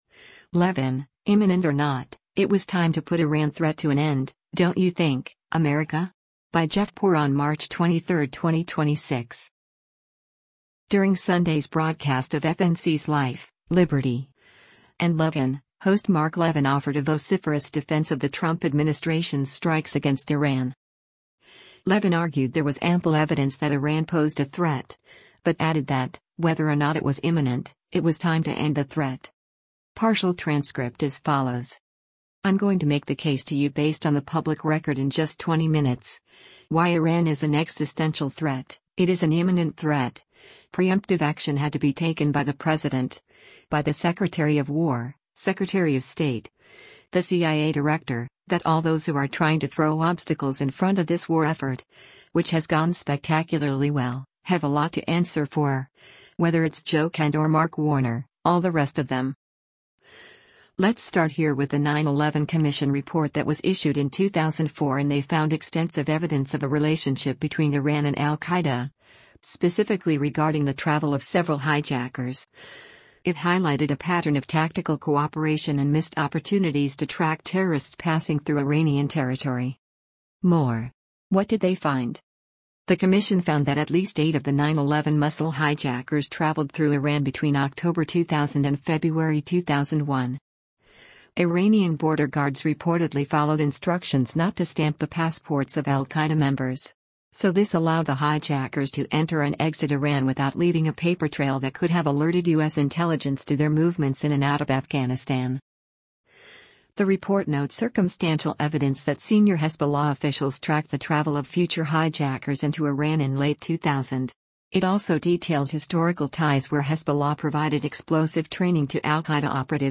During Sunday’s broadcast of FNC’s “Life, Liberty & Levin,” host Mark Levin offered a vociferous defense of the Trump administration’s strikes against Iran.